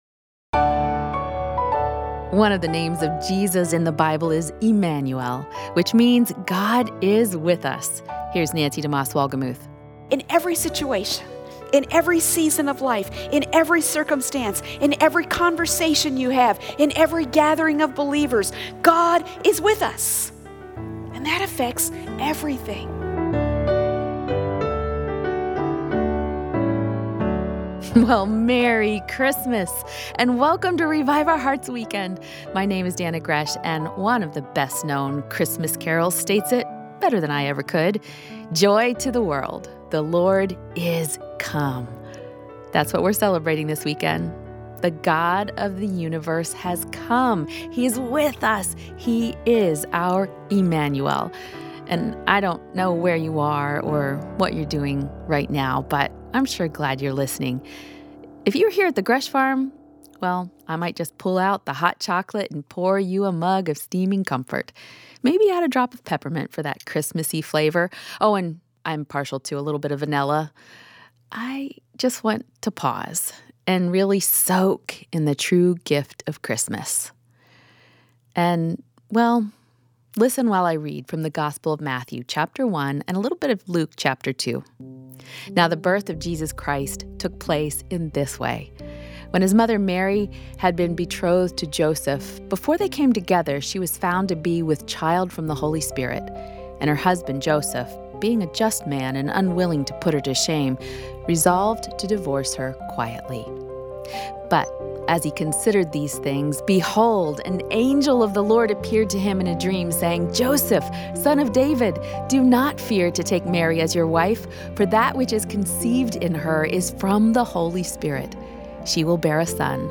Celebrate the birth of Immanuel with Scripture, songs, and some special (twin toddler) guests.